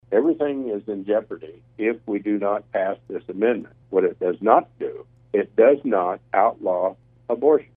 On KMAN’s weekly legislative update Friday morning, 51st District Rep. Ron Highland of Wamego, who voted in favor of the amendment, called the proposal a necessity to protect laws passed by the legislature involving a woman’s right to know and inspections of abortion clinics.